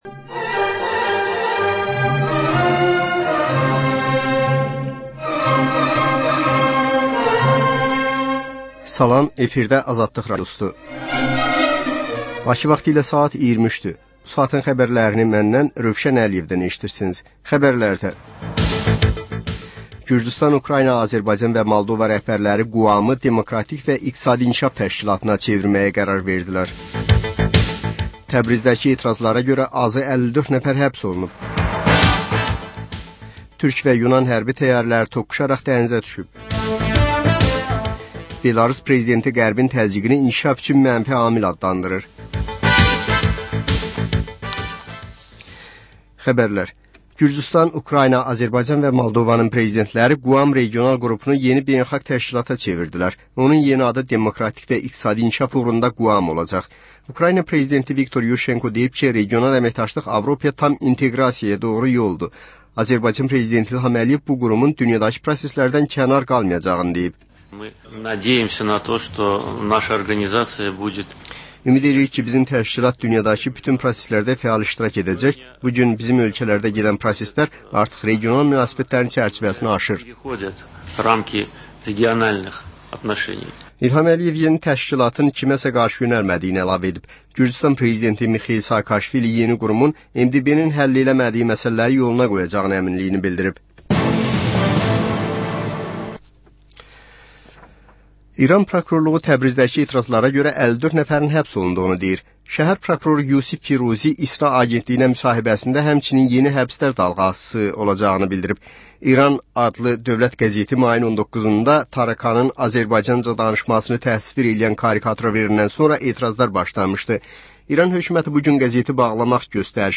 Xəbərlər, reportajlar, müsahibələr. Hadisələrin müzakirəsi, təhlillər, xüsusi reportajlar.